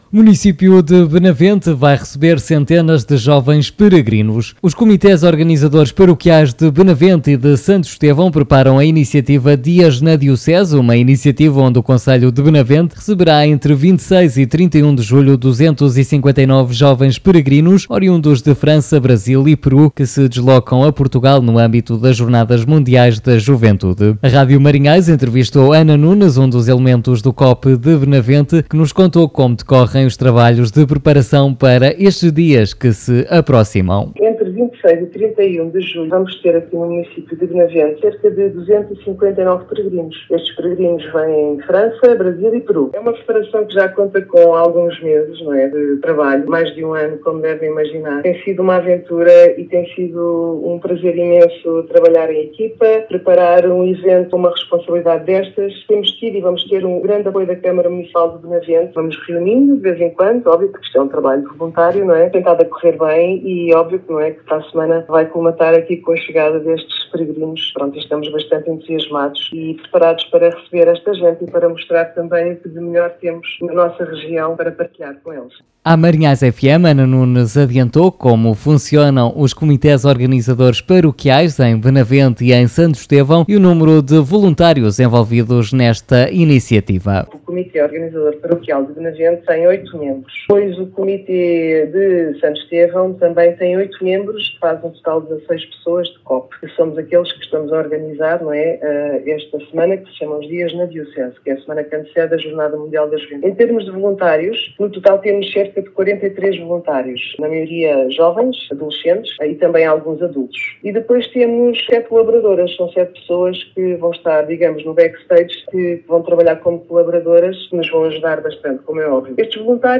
A Rádio Marinhais entrevistou